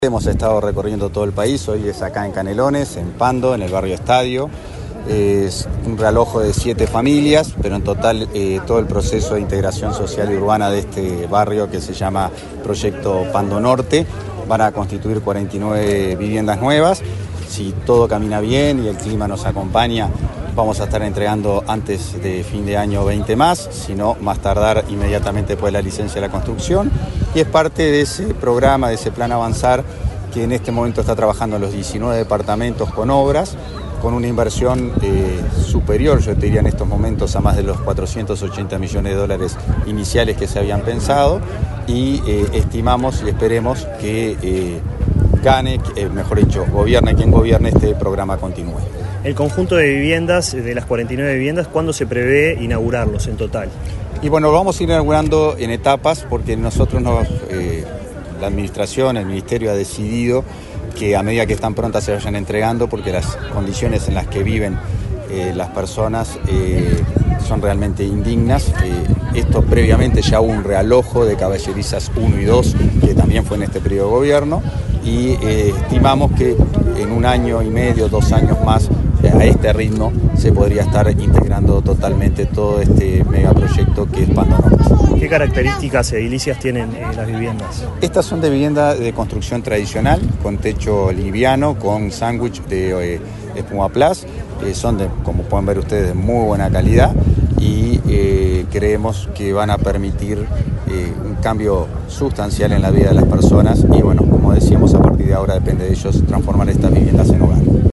Entrevista al subsecretario de Vivienda, Tabaré Hackembruch